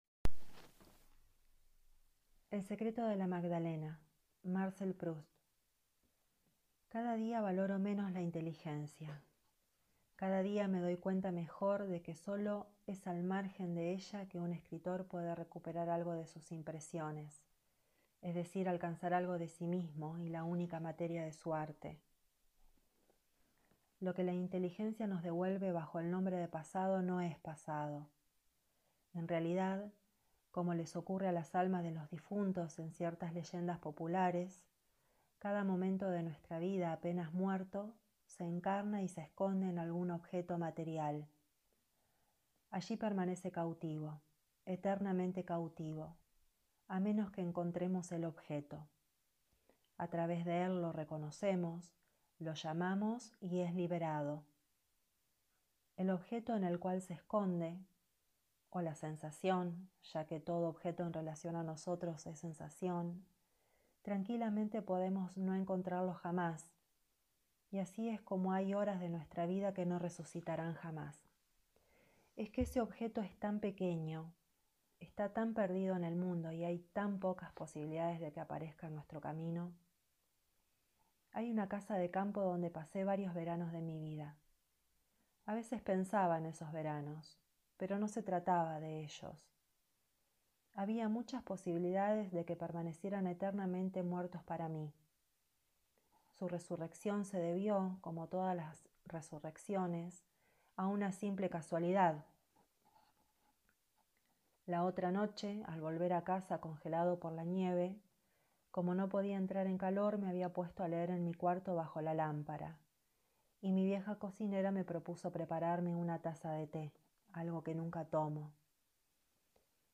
«El secreto de la magdalena» de Marcel Proust leído por